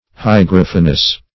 Search Result for " hygrophanous" : The Collaborative International Dictionary of English v.0.48: Hygrophanous \Hy*groph"a*nous\, a. [Gr.
hygrophanous.mp3